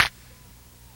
Perc (5).wav